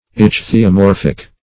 Search Result for " ichthyomorphic" : The Collaborative International Dictionary of English v.0.48: Ichthyomorphic \Ich`thy*o*mor"phic\, Ichthyomorphous \Ich`thy*o*mor"phous\, a. [See Ichthyomorpha .]